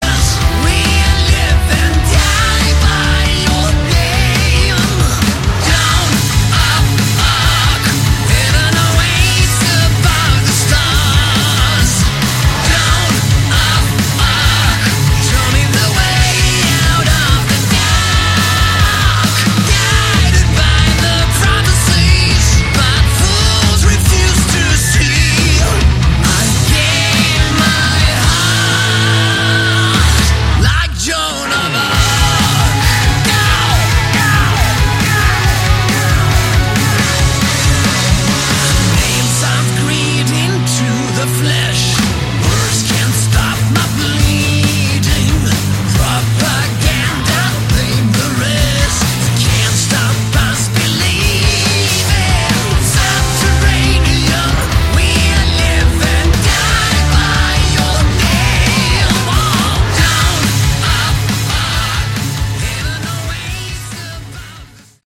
Category: Hard Rock
vocals, guitar, keyboards
guitar, bass, keyboards, backing vocals
drums